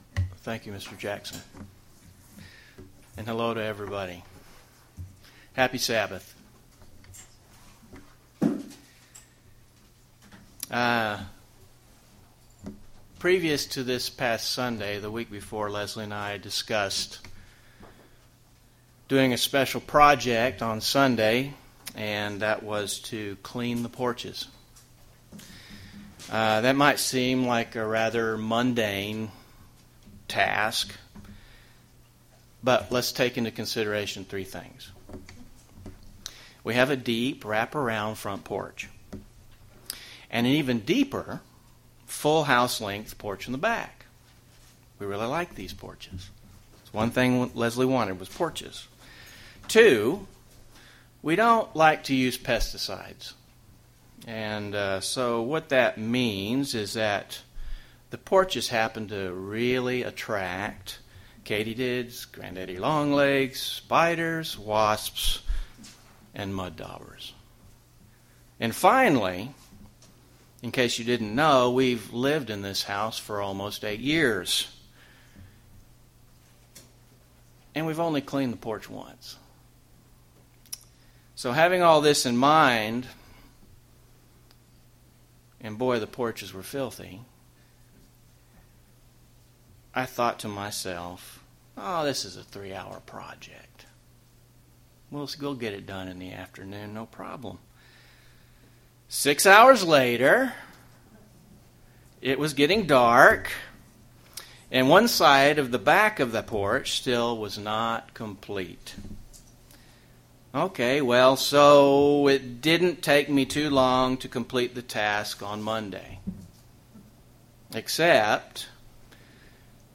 UCG Sermon maintenance building character Overcoming Sin Notes PRESENTER'S NOTES We decided to clean the porch this past Sunday.